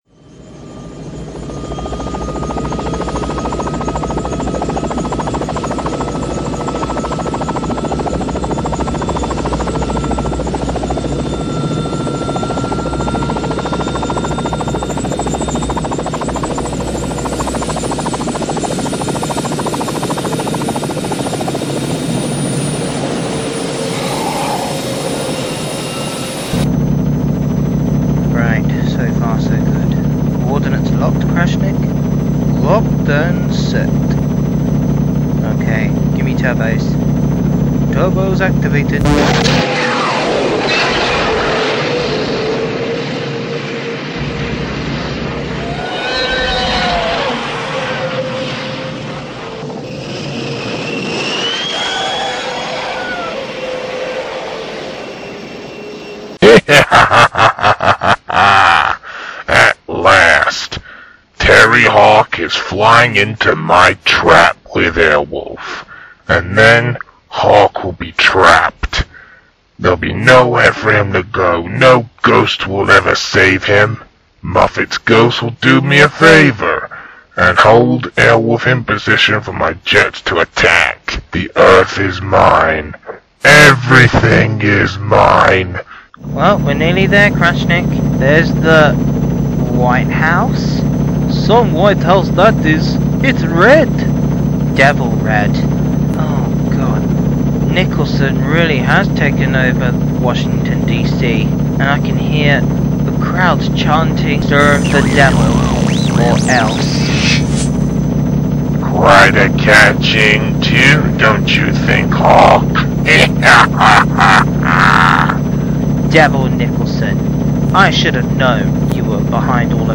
also include the sound effects too
Voice work done by me